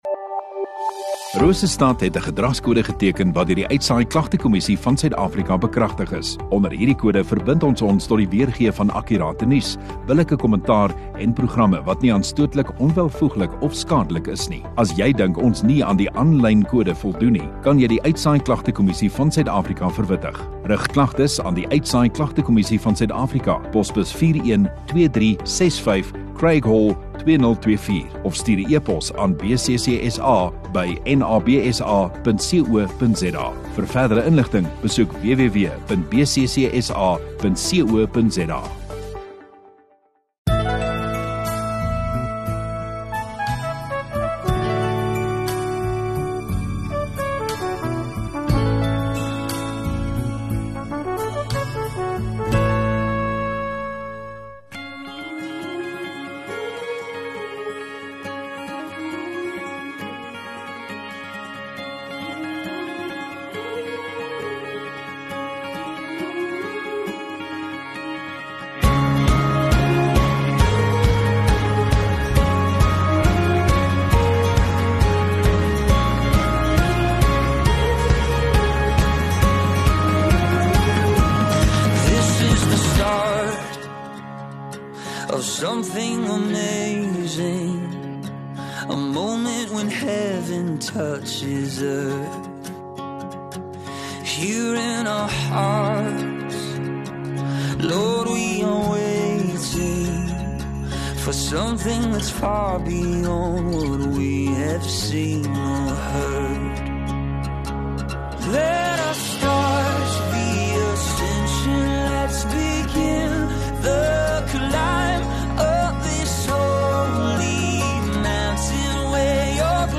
2 Mar Sondagoggend Erediens